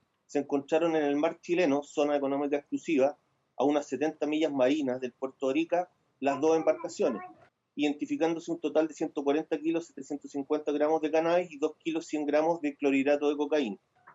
El fiscal Occidente, Pablo Sabaj, puntualizó que se incautaron 140 kilos de creepy y 2 kilos de cocaína.